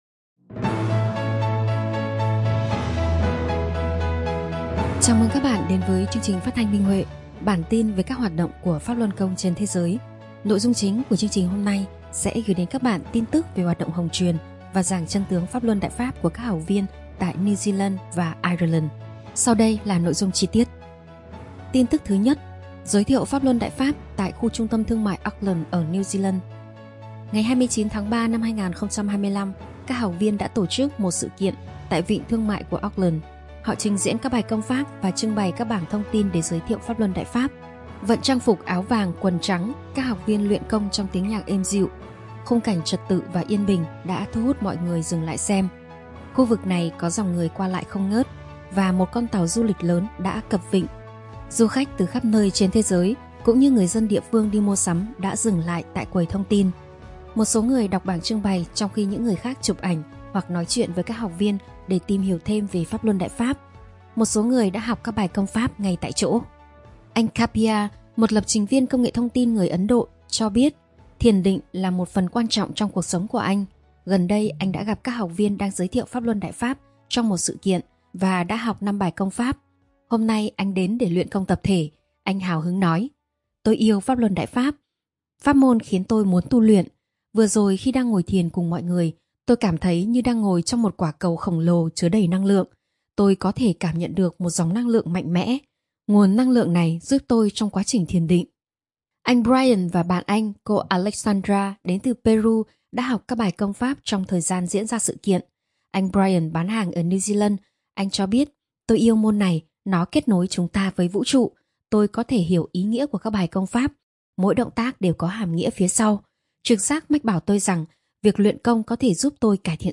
Chương trình phát thanh số 304: Tin tức Pháp Luân Đại Pháp trên thế giới – Ngày 4/4/2025